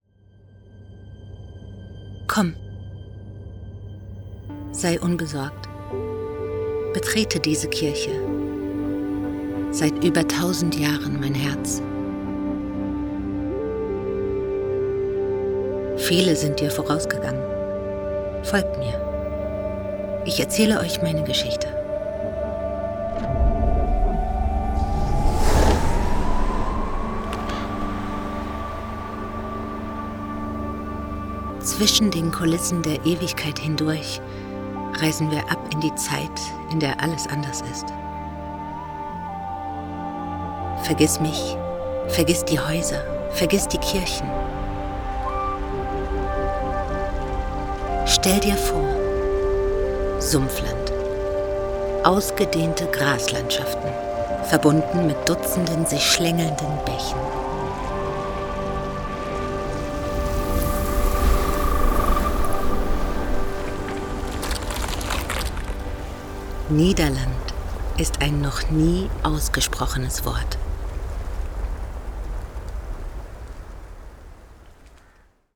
Meine Stimme ist warm, klar und vielseitig einsetzbar – ob für Werbung, Imagefilme, E‑Learnings, Audioguides oder Dokumentationen.
Kund:innen schätzen meinen natürlichen, empathischen Ton, die präzise Aussprache und eine zuverlässige, unkomplizierte Zusammenarbeit.
Broadcast‑ready Aufnahmen entstehen in meinem eigenen Studio in Berlin.
Sprechprobe: Sonstiges (Muttersprache):